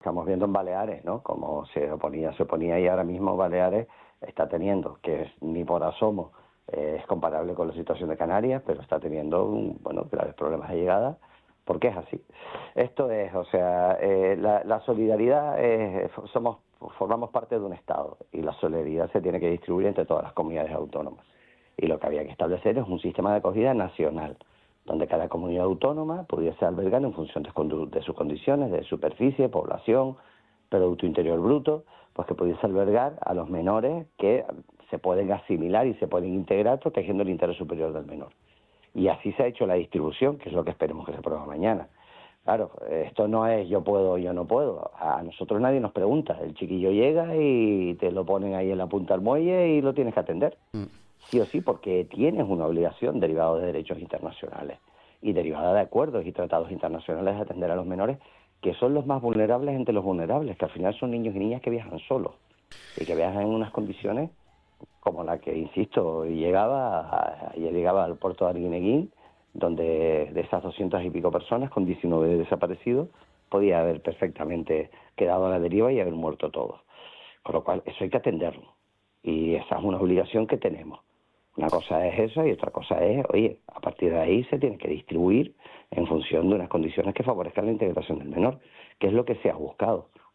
Escolta aquí l’entrevista completa al president de Canàries, Fernando Clavijo, al programa ‘Arriba las Palmas’ a UD Radio.